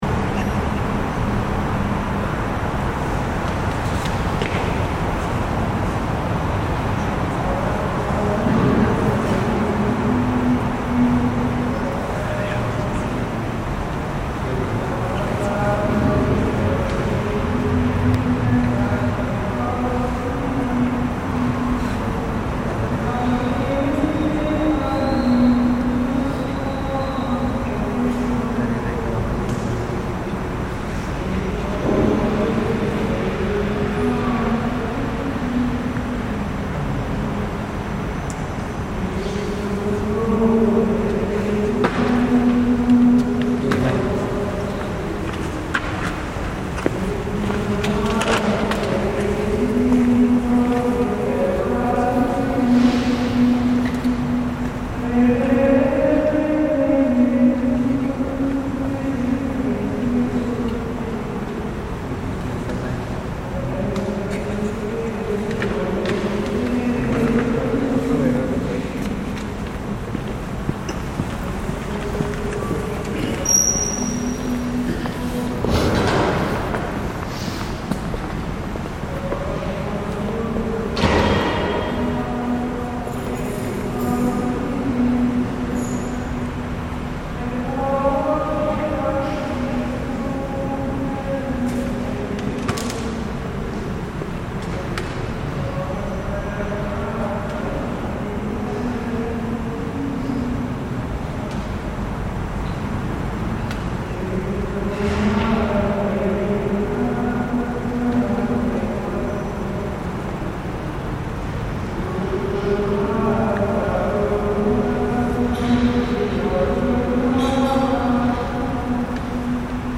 The sound of religious singing in the Basilica di Santa Maria in Rome. A calm evening in February before the Basilica shuts its doors for the night, I wandered into the near-empty church to record the soothing sounds of religious hymns that seeped into the dimly lit streets of Rome.